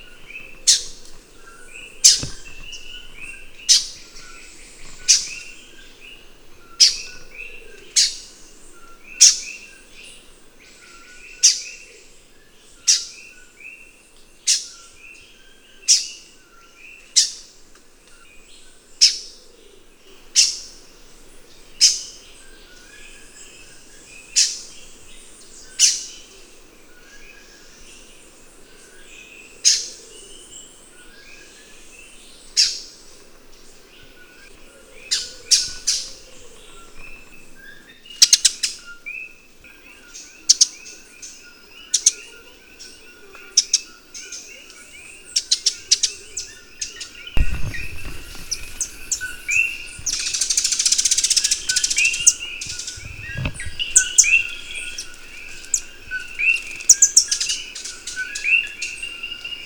"Puerto Rican Tanager"
Nesospingus speculiferas
Especie Endémica de Puerto Rico
Ruidosa ave forestal de partes dorsales color marrón oliváceo, más oscuro en la parte superior de la cabeza.
llorosa.wav